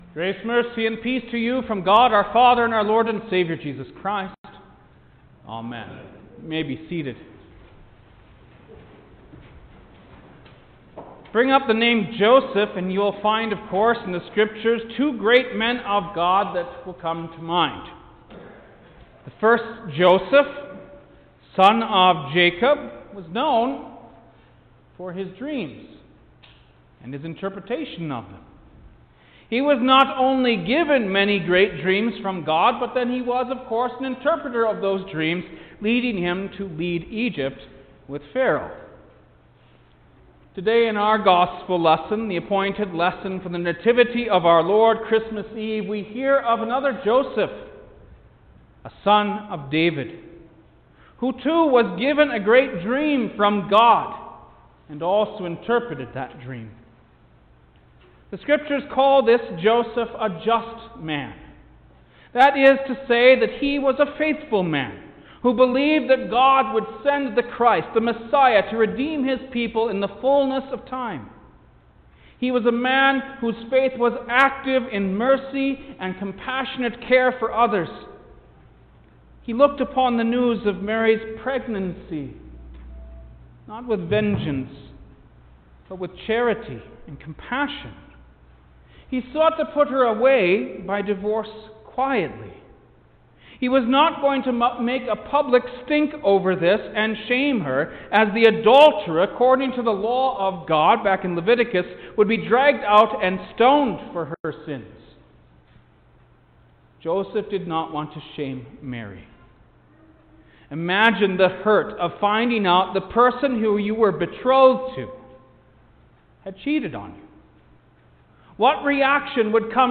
December-20_Christmas-Eve-Noon-Service-Sermon.mp3